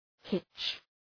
Προφορά
{hıtʃ}